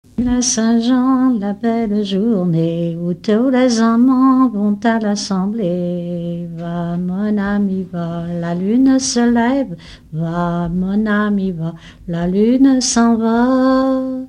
en duo
Genre laisse
Pièce musicale inédite